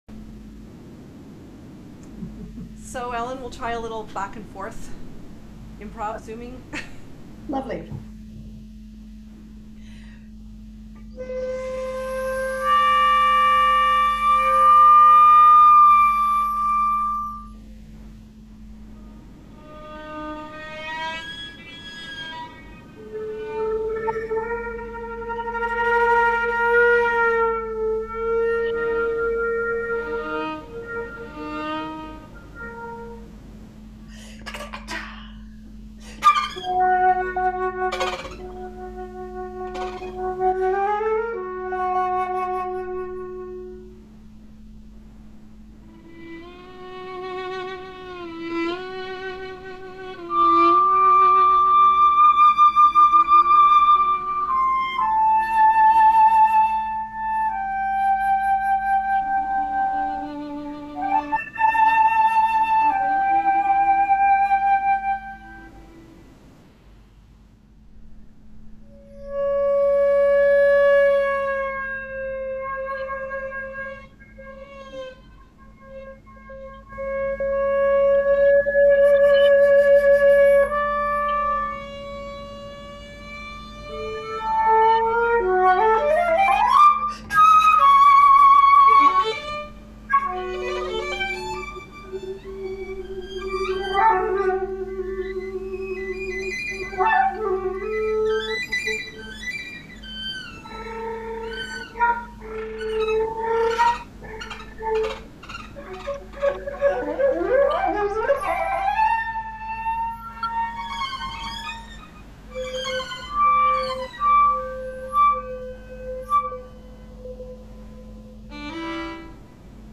Because of this style of improv, we were able to play at the same time, but unfortunately Zoom sometimes silenced a quieter voice.
This was recorded July 21, 2021 over Zoom.